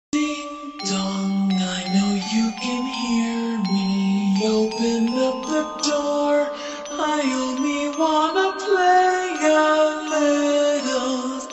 Horror Versions Of The Peppa Sound Effects Free Download